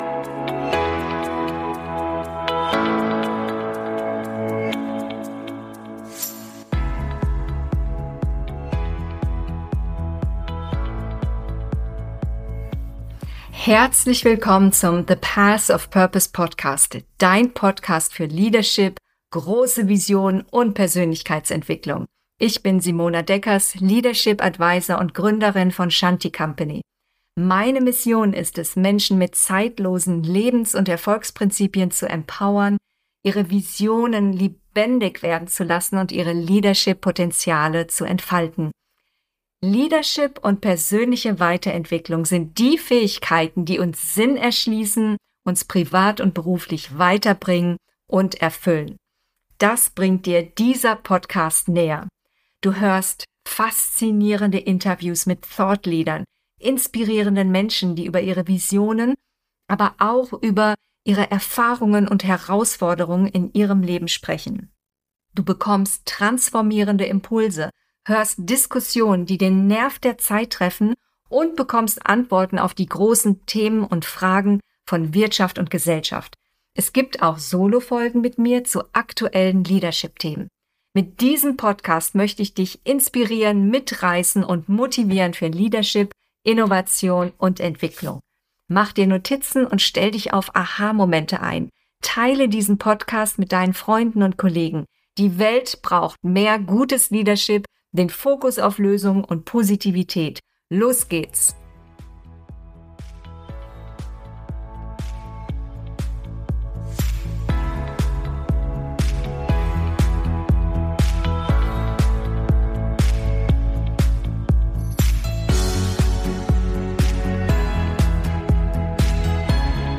Strategie, Kultur und Business: Einflussfaktor People & Culture - Interview